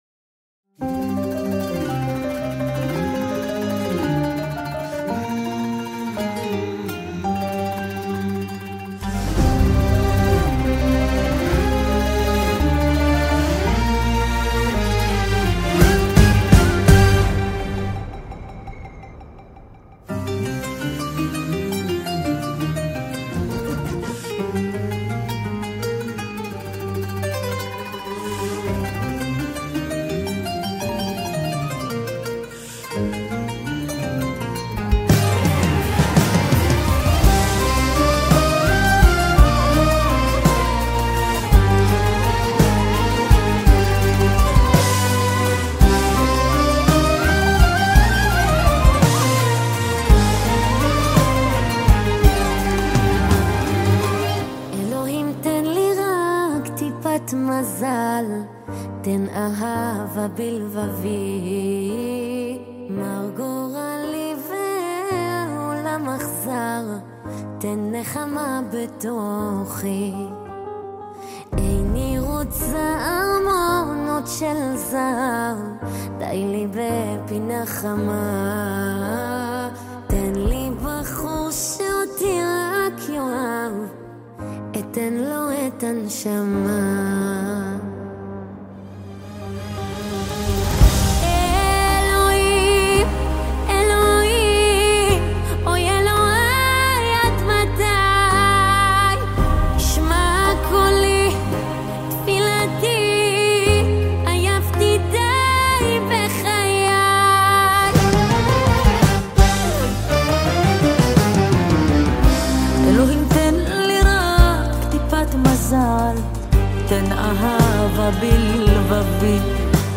תופים
בס
קלידים
גיטרה
סקסופון
דרבוקה
כינורות, חלילים וקאנון